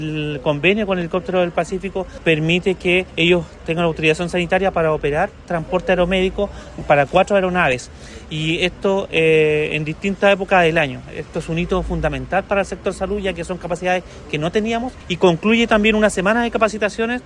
Así lo detalló a Radio Bío Bío el seremi de Salud local, Andrés Cuyul.